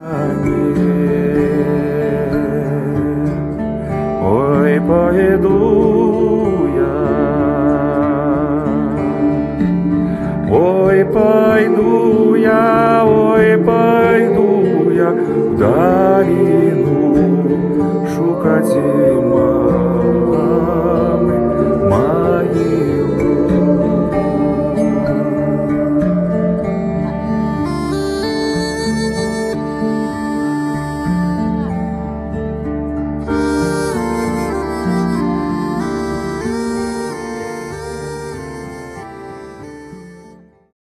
perkusja, darabuka, dżambe, bębny, gong